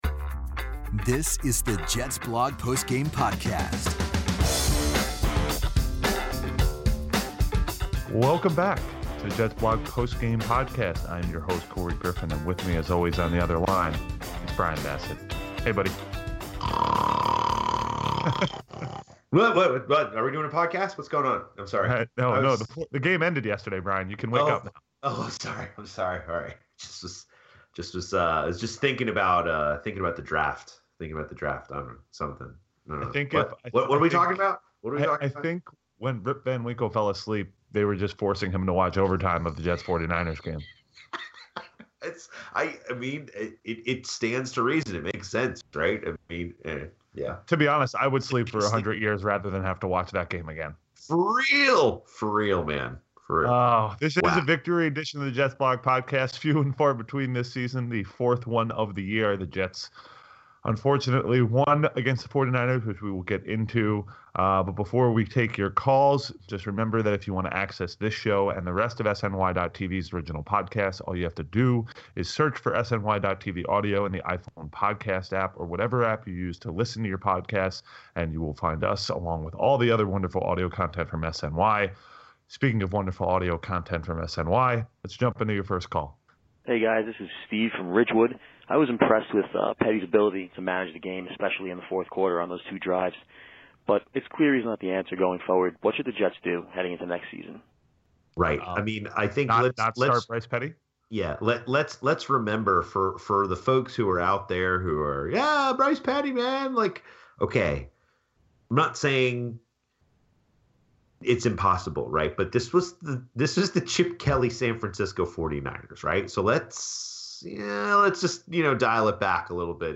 They take your calls covering Bryce Petty, Bilal Powell, the defense, and rooting for wins versus a high draft pick.